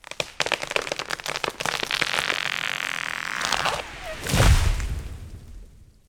tree-falling-2.ogg